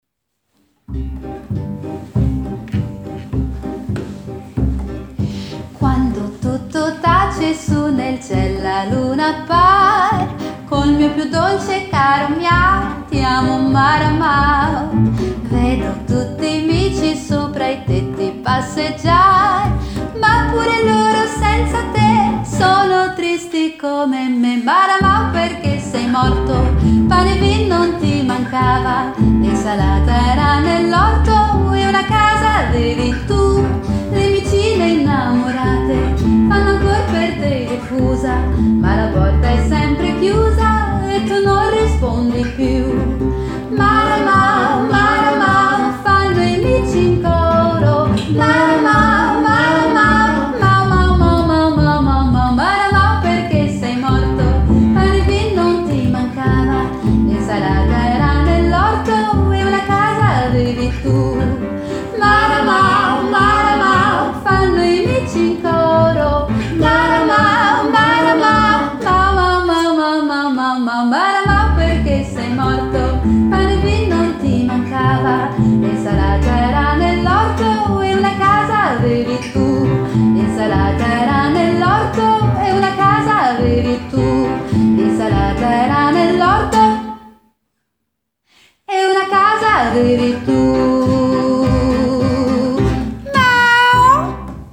Intanto un assaggio delle nostre prove.
Spettacolo musicale.
Spettacolo musicale con le più belle canzonette swing dell’Italia degli anni ’40, ’50 e ’60
Recital musicale
raccontando con musica e parole tre decenni al ritmo di swing
sulle note suonate dalla chitarra scanzonata
contrabbasso